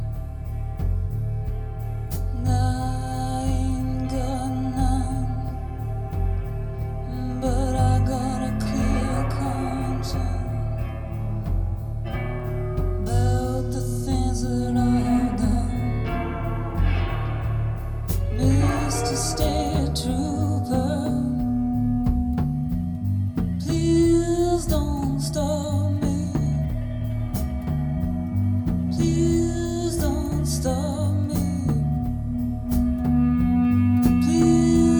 Жанр: Рок / Альтернатива / Кантри